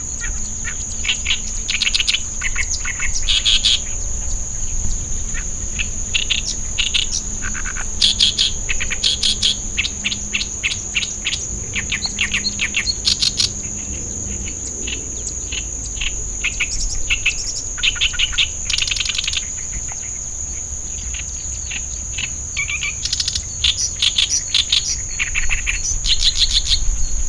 • オオヨシキリ（ヒタキ科）